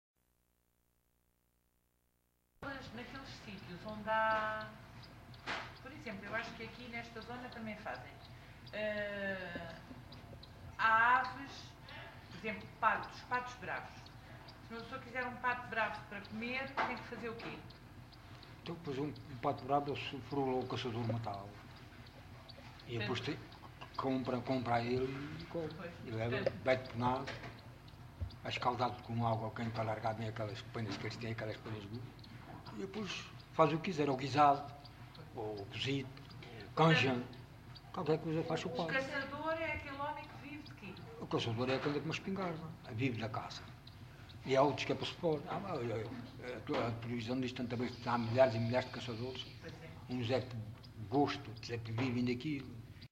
LocalidadeAlcochete (Alcochete, Setúbal)